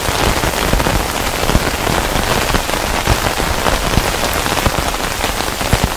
rain_helm.ogg